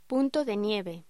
Locución: Punto de nieve
voz
locución